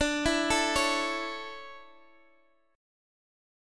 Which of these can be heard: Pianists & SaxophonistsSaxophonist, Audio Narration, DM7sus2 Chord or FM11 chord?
DM7sus2 Chord